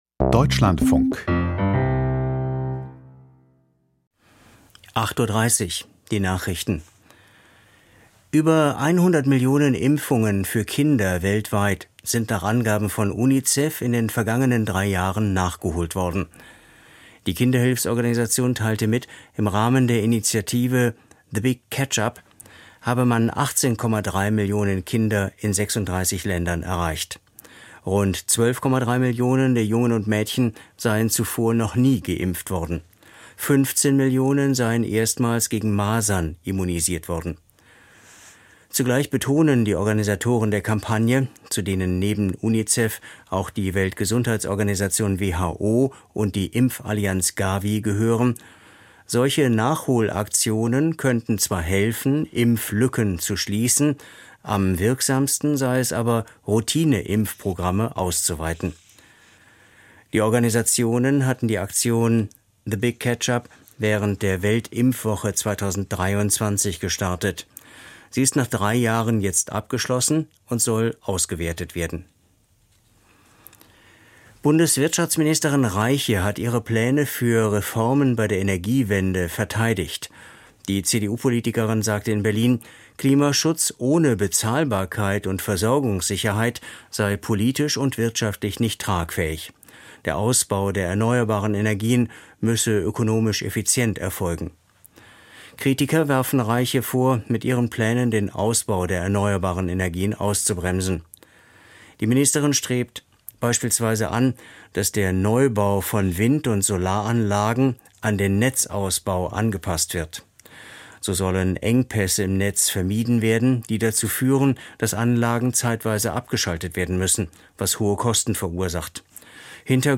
Die Nachrichten vom 24.04.2026, 08:30 Uhr
Aus der Deutschlandfunk-Nachrichtenredaktion.